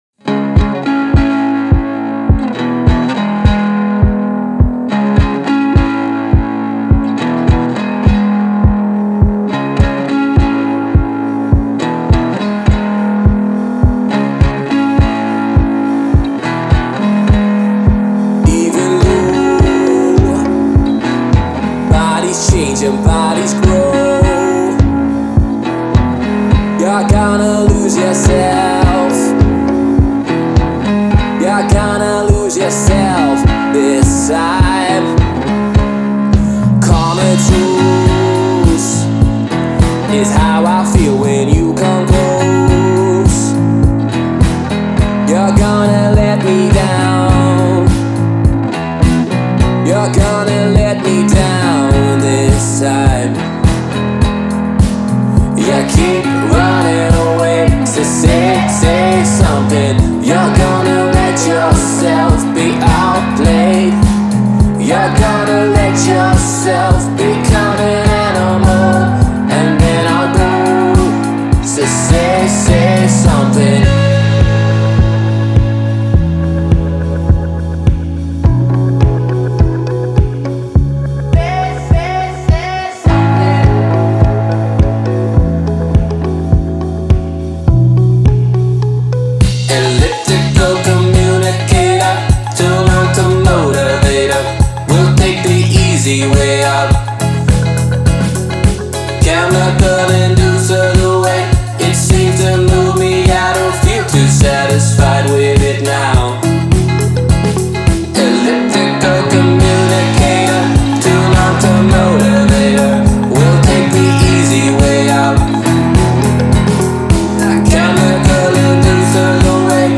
Jeune groupe irlandais fondé en 2017